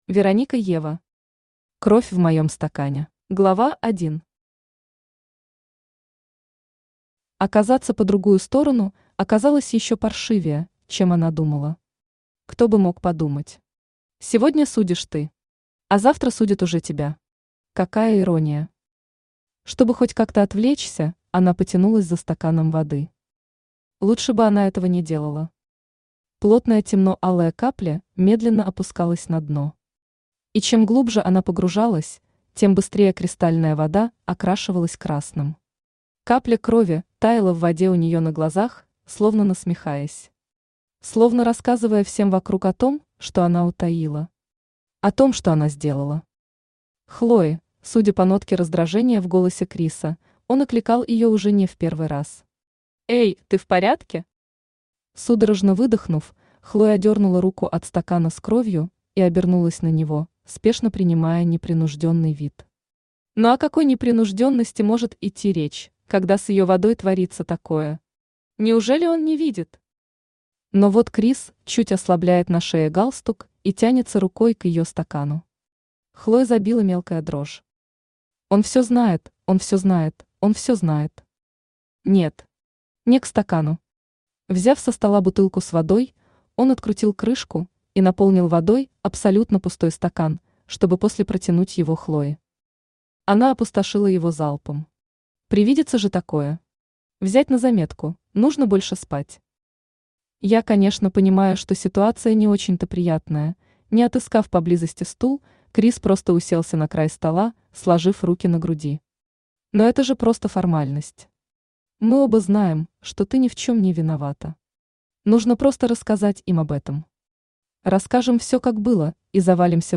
Aудиокнига Кровь в моем стакане Автор Вероника Ева Читает аудиокнигу Авточтец ЛитРес.